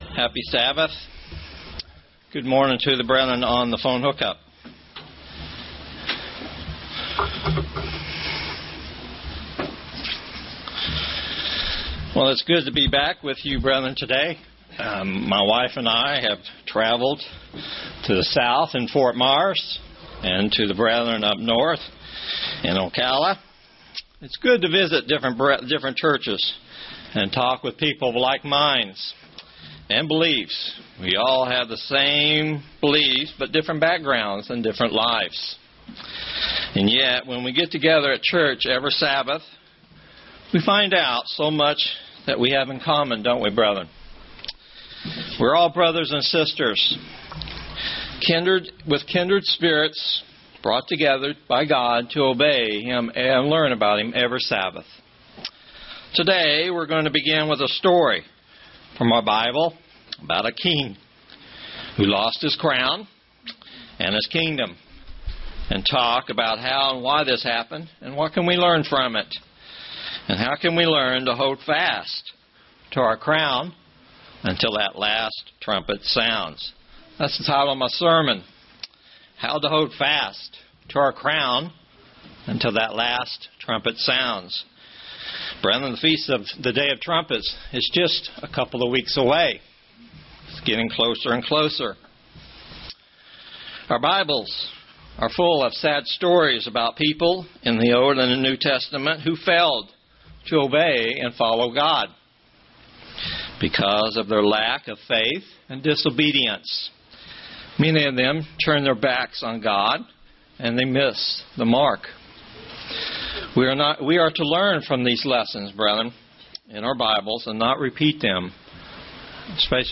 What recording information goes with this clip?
Given in St. Petersburg, FL